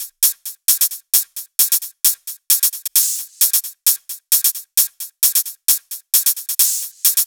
VFH3 132BPM Elemental Kit 9.wav